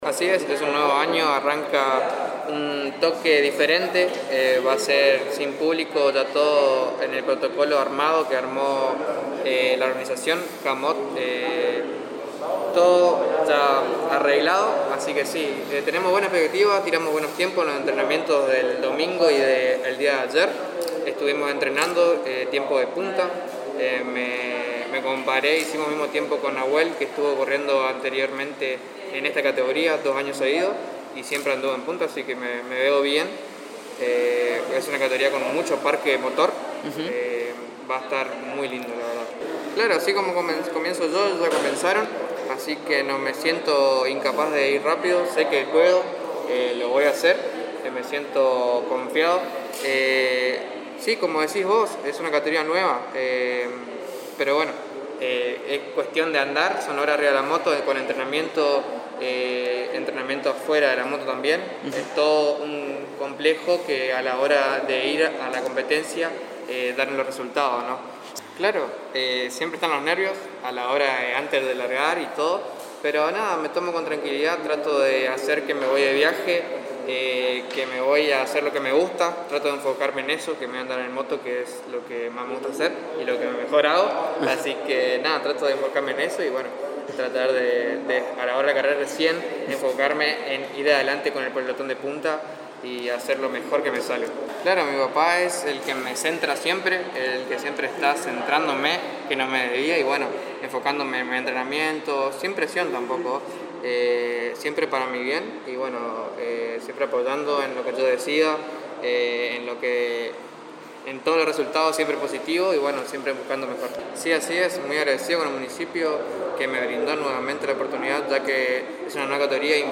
En una charla en exclusiva para FM Band News 89.7 y la Agencia de Noticias Guacurarí comentó cómo se viene preparando y sus expectativas en este año que es totalmente diferente y con protocolos armados para que se desarrolle esta competencia, también comentó que estuvo el domingo y en la jornada de ayer donde se realizaron excelentes tiempos.